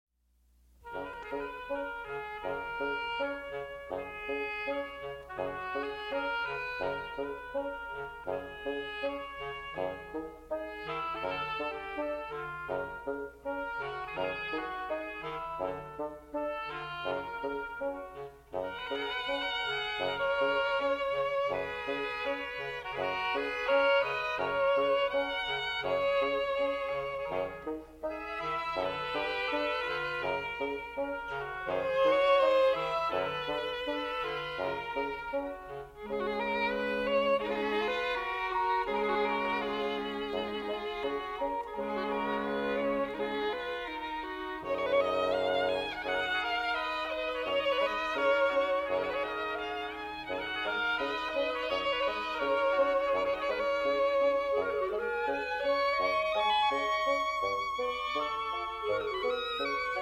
William Kincaid - Flute
Oboe
Clarinet
French Horn
Bassoon
Violin
English Horn